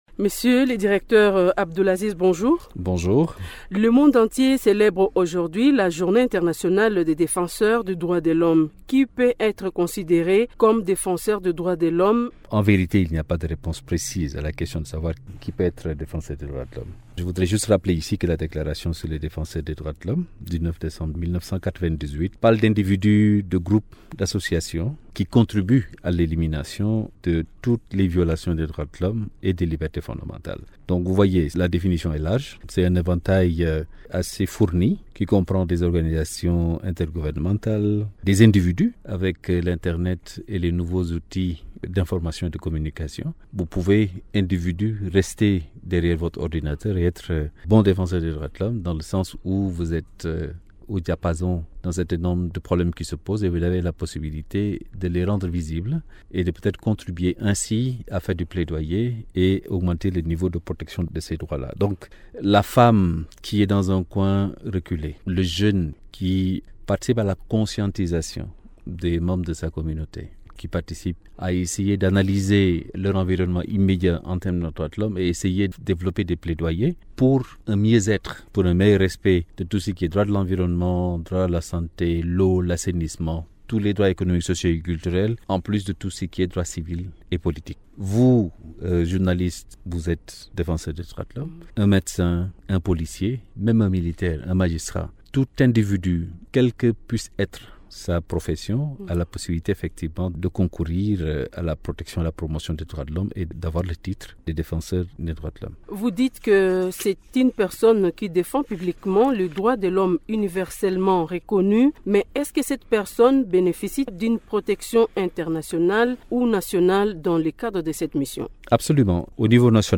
Il l’a dit au cours d’une interview accordée à Radio Okapi, à l’issue d’un atelier de réflexion de deux jours ouvert mardi dernier à Goma au Nord-Kivu.